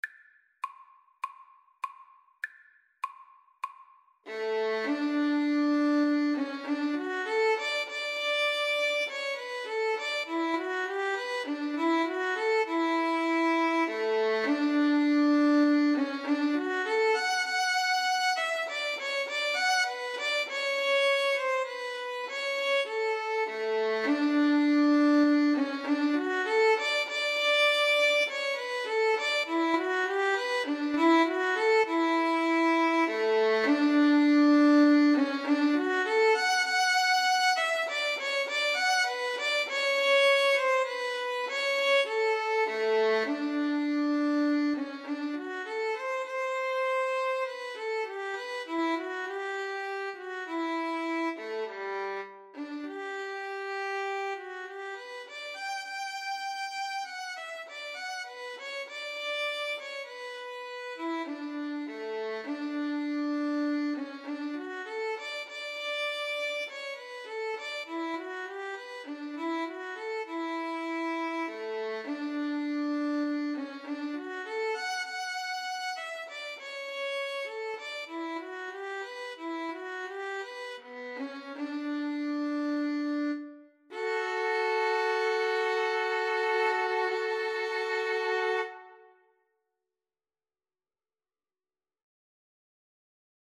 4/4 (View more 4/4 Music)
Classical (View more Classical Violin-Cello Duet Music)